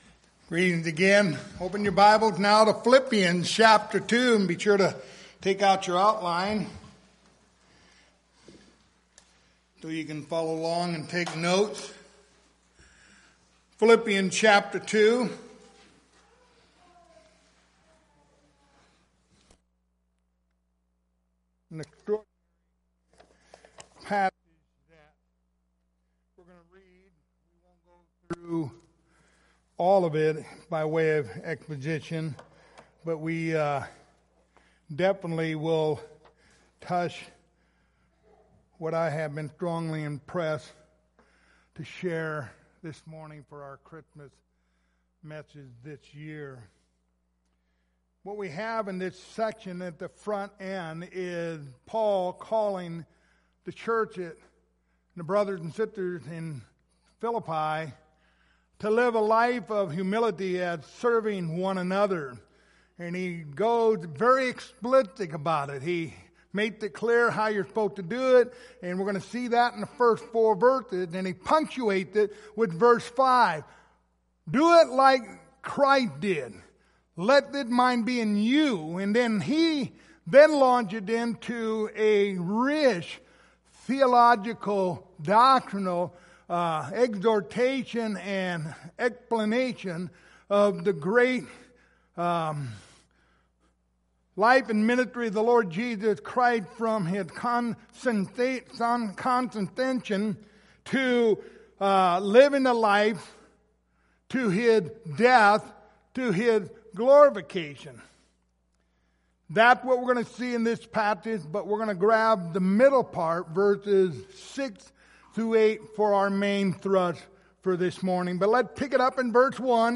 Passage: Philippians 2:1-11 Service Type: Sunday Morning